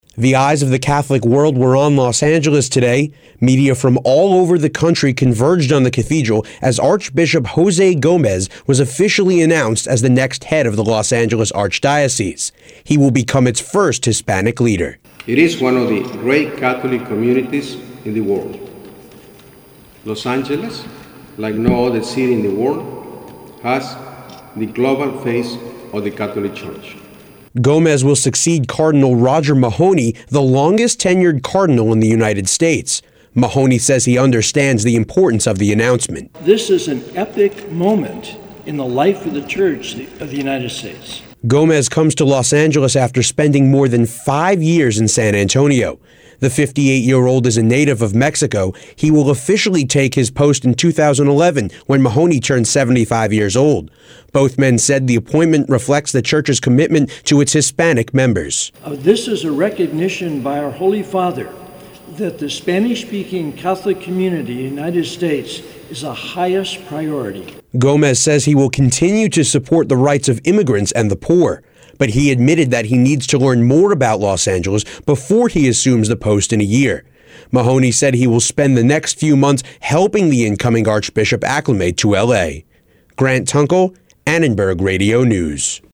"It is one of the great Catholic communities in the world," Gomez said Monday at the Cathedral of Our Lady of the Angels."Los Angeles, like no other city in the world, has the global face of the Catholic Church."
He and Mahony addressed dozens of members of the media from across the country, speaking in both English and Spanish.